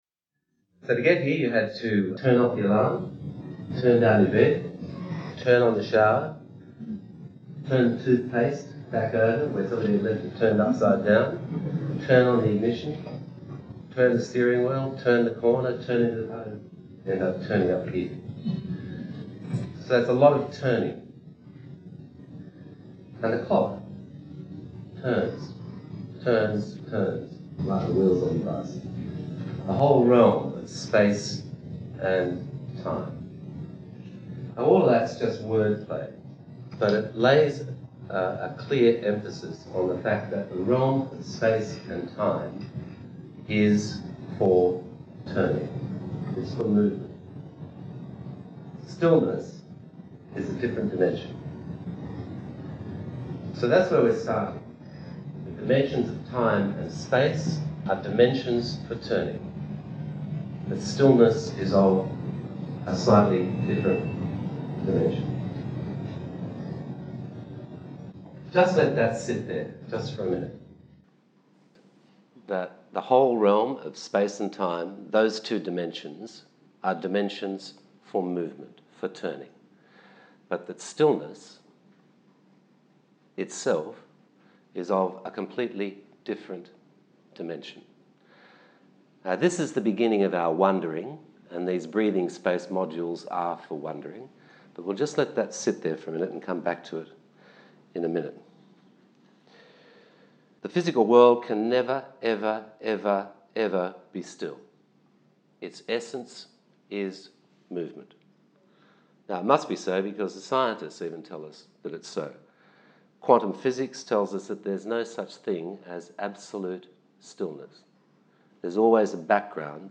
Breathing Space A series of talks on subjects that matter